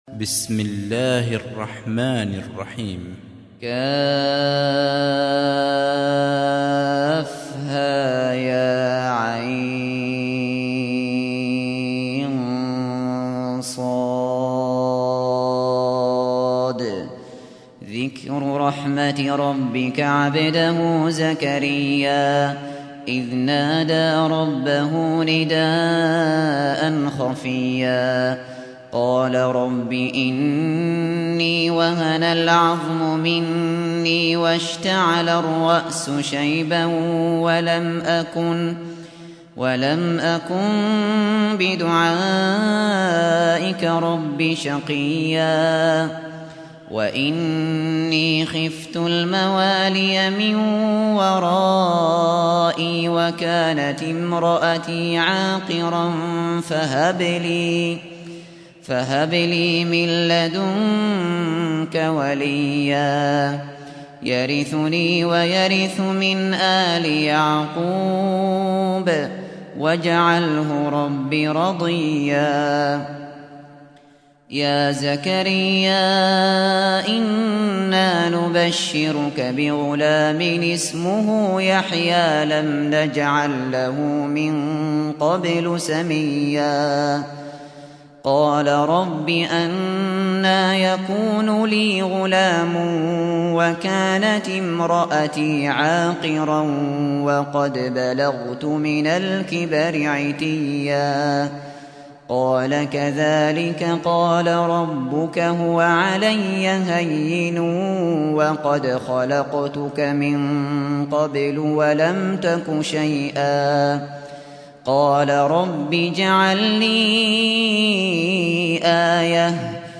سُورَةُ مَرۡيَمَ بصوت الشيخ ابو بكر الشاطري